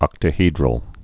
(ŏktə-hēdrəl)